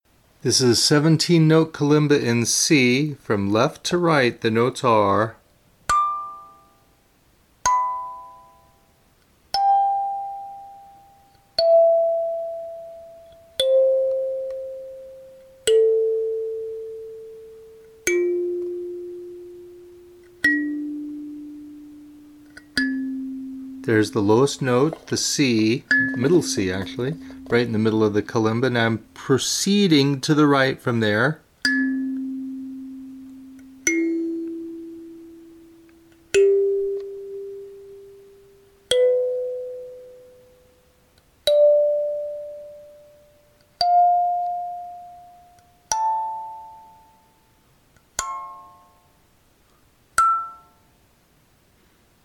These sound recordings – where you hear me playing each note, properly tuned, slowly, from left to right, for some of our most popular kalimbas – should help you transition into successfully maintaining your instrument’s tuning.
If the instrument has two rows of tines, the lower row is played first, left to right, and then the upper row is played, left to right.
17-Note Kalimba in C, Standard Tuning:
Tuning17C.mp3